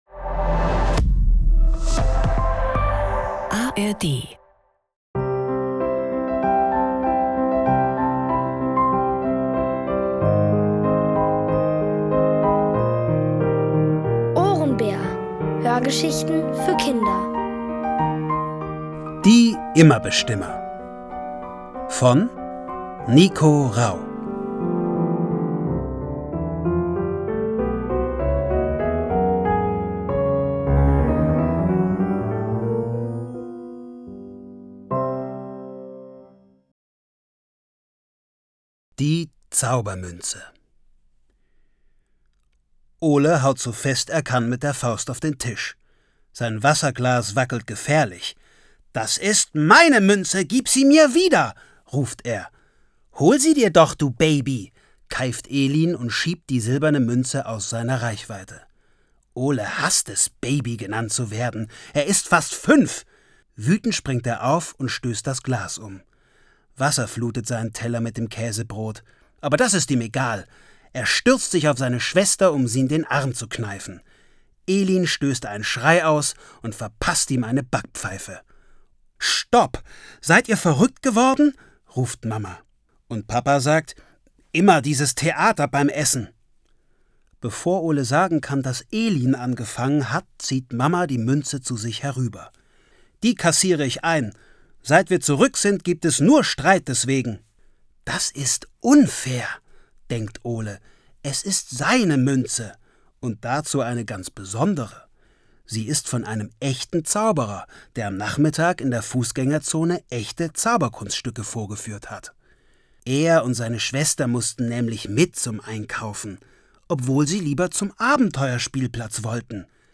Die Immer-Bestimmer | Die komplette Hörgeschichte! ~ Ohrenbär Podcast